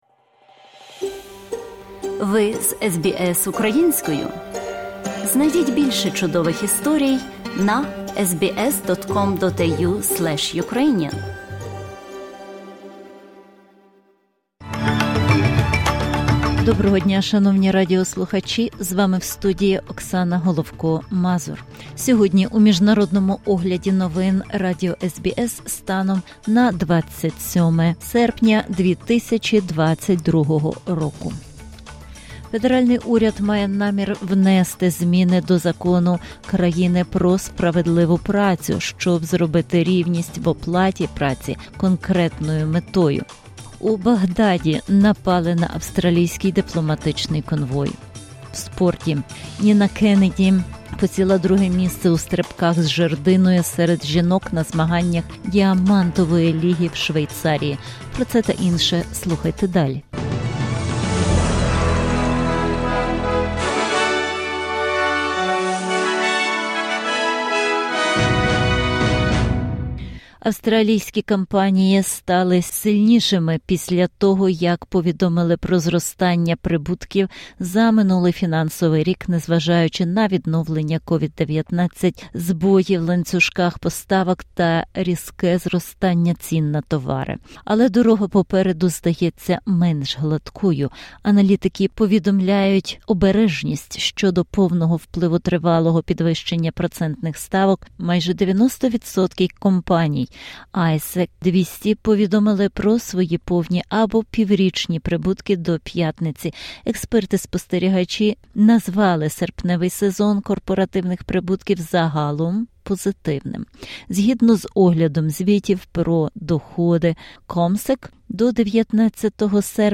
SBS News in Ukrainian - 27/08/2022